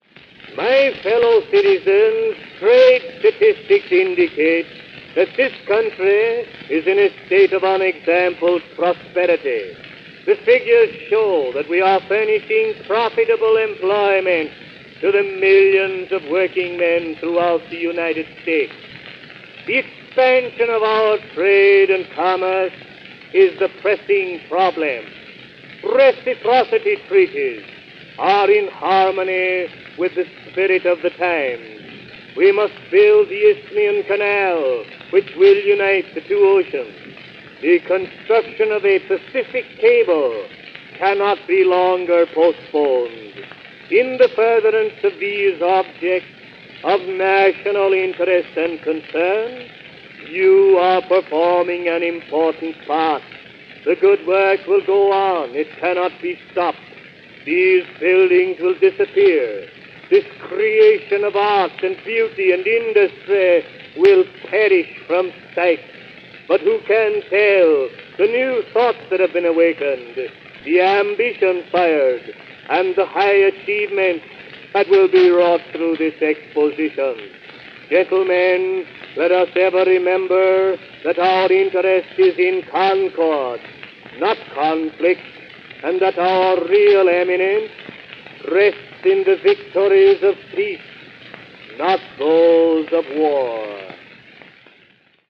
This recording, long thought to be the actual voice of McKinley, was the speech he delivered shortly before the shooting. It was later discovered the recording was a re-creation by distinguished actor Len Spencer, and the disc (and cylinder) was offered as a memorial to the slain President.